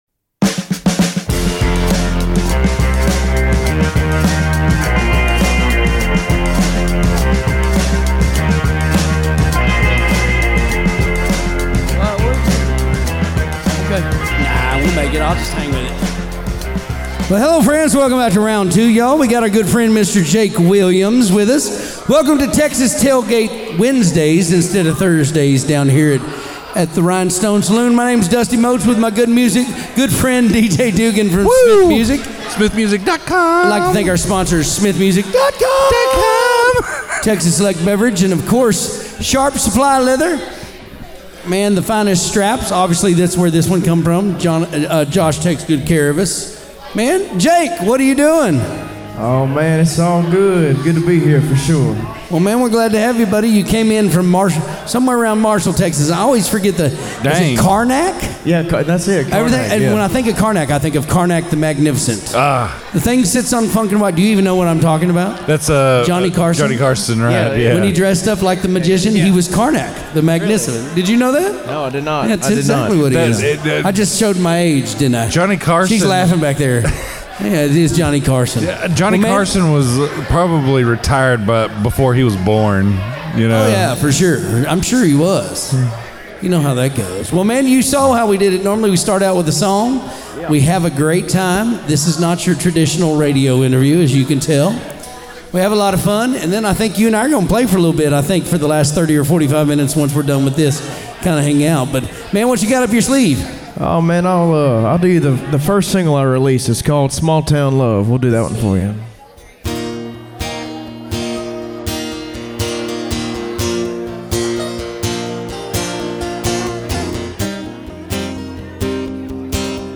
at The Rhinestone Saloon in the Fort Worth Stockyards. They discuss living in a small town, Elvis Presley, and getting your steps in.